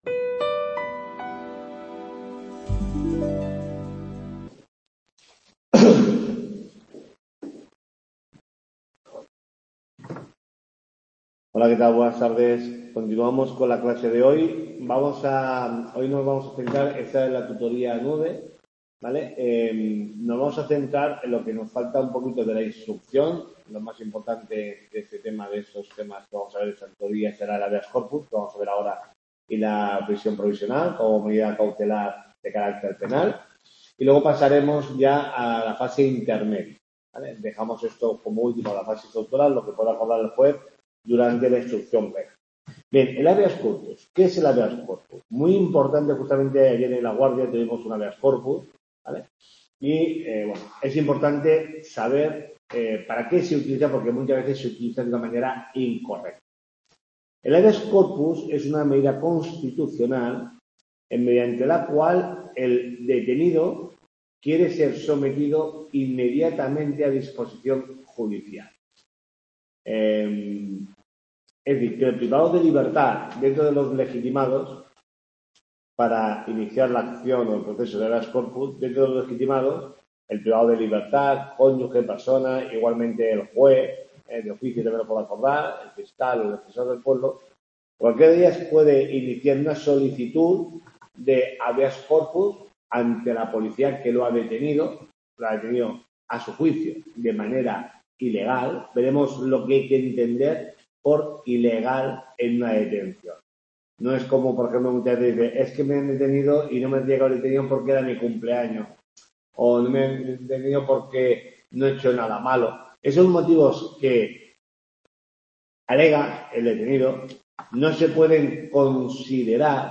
TUTORIA 9